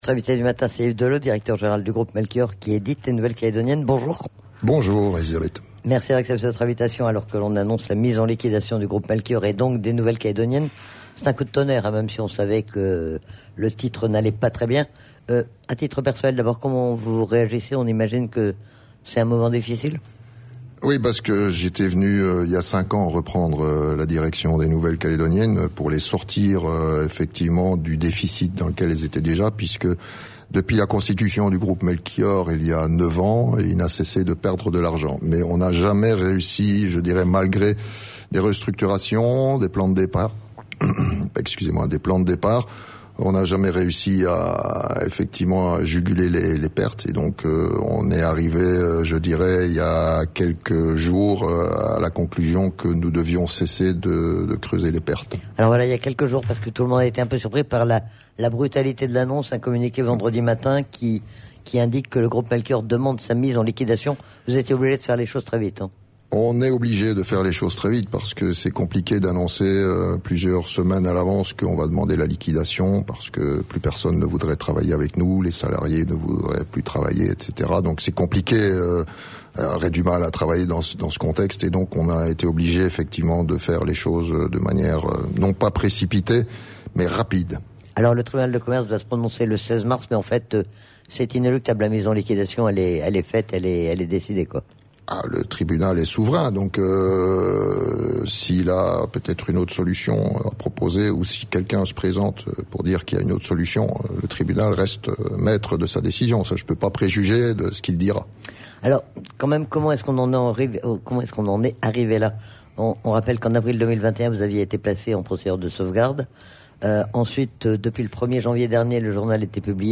INVITE DU MATIN